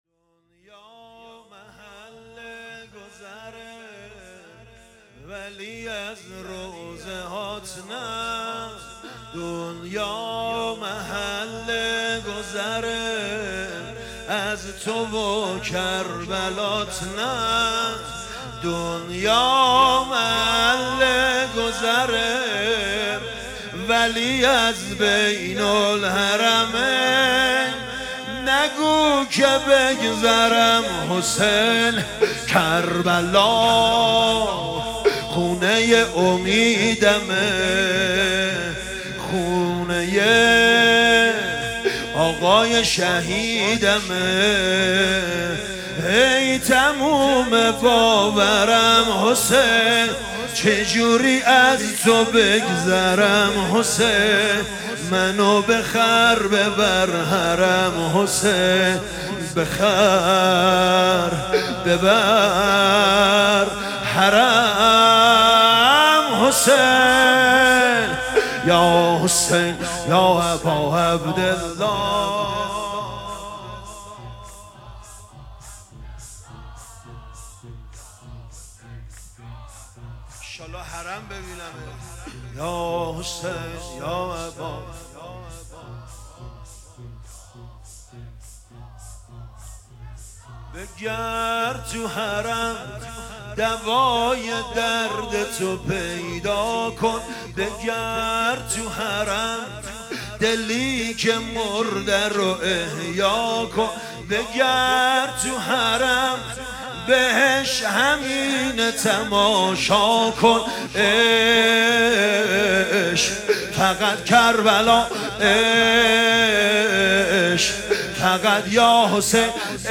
شب دوم محرم الحرام 1443